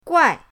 guai4.mp3